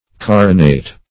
Carinate \Car"i*nate\, Carinated \Car"i*na`ted\a. [L. carinatus,